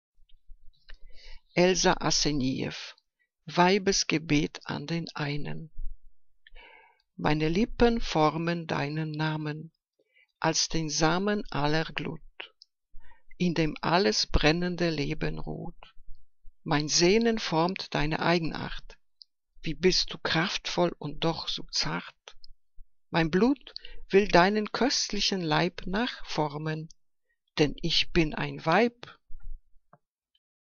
Liebeslyrik deutscher Dichter und Dichterinnen - gesprochen (Elsa Asenijeff)